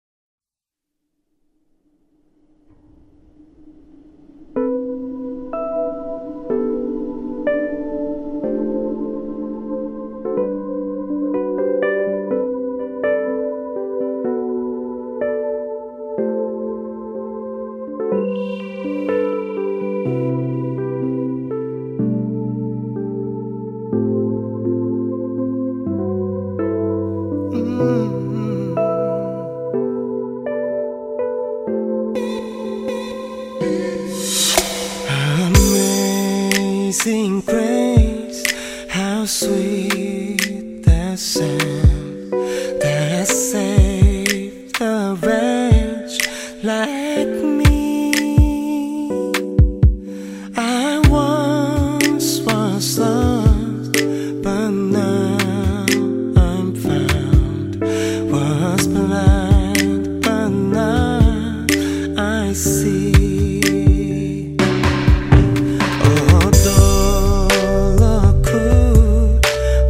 R&Bからジャズまで幅広い音楽的要素を持ち